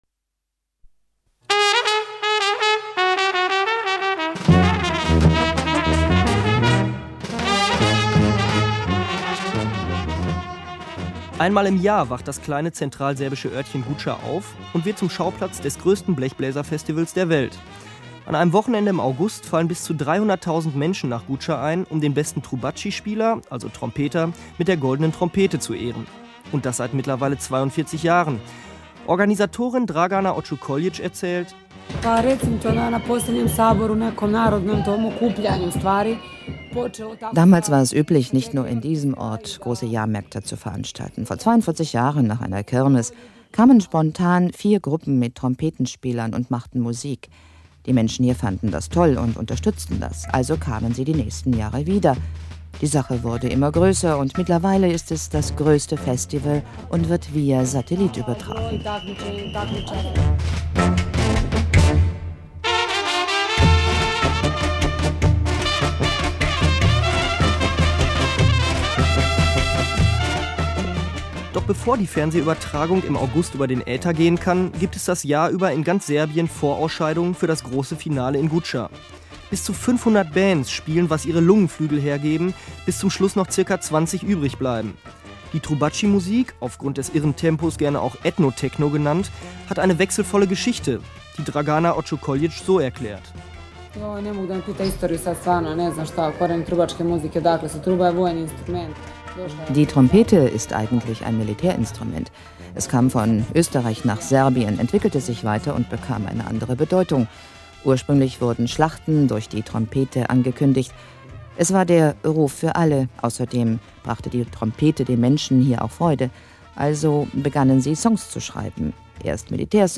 Beiträge für WDR Funkhaus Europa
·         Guca (Zigeuner-Blasmusik-Festival in Serbien)